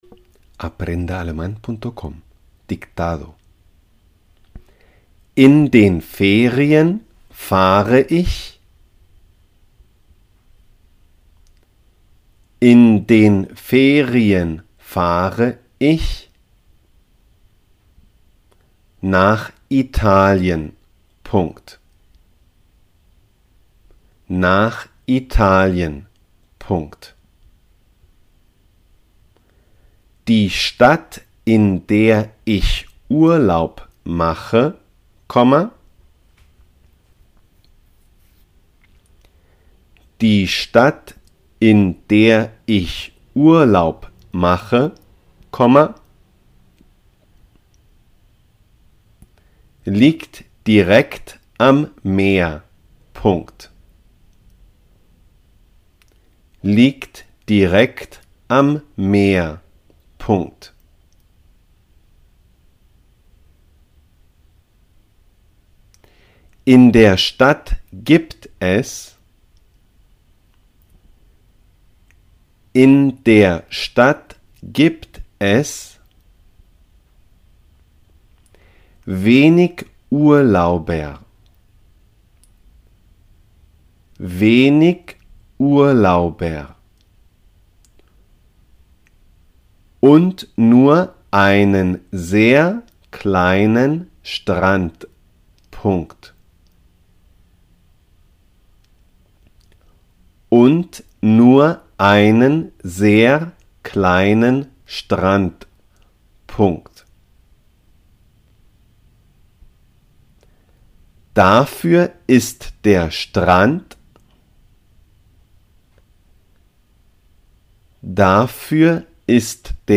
der Strand – La playa .dictado
La-playa-der-Strand-dictado-en-aleman-AprendeAleman.com_-1.mp3